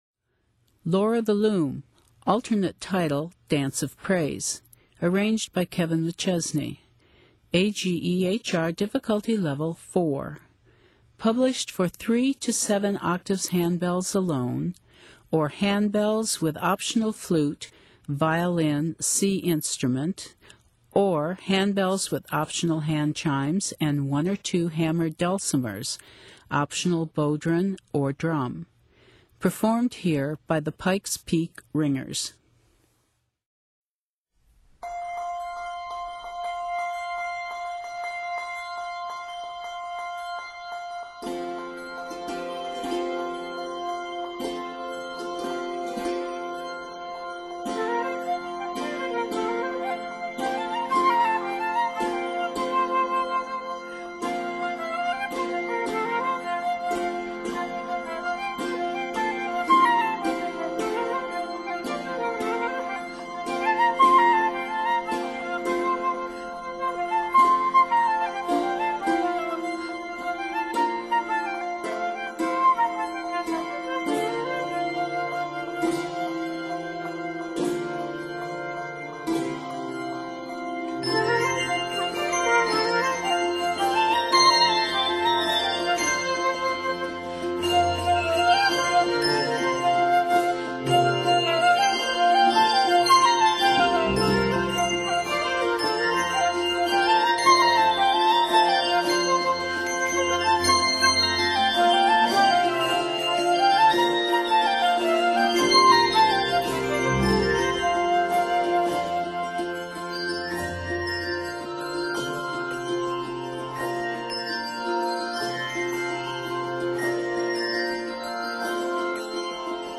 exhilarating and awe-inspiring Celtic dance-like piece
handbells